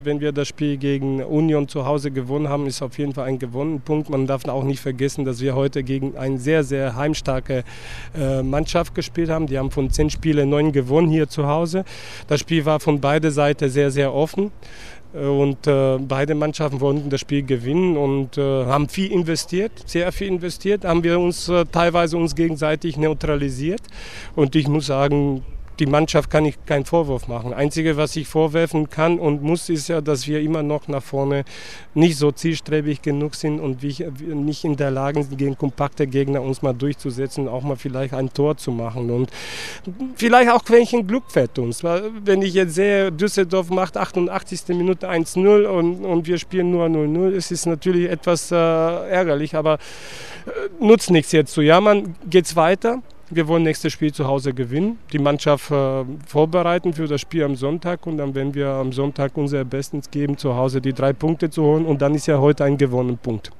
Audio-Kommentar